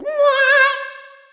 Sfx Insane Hatty Swirl Sound Effect
sfx-insane-hatty-swirl.mp3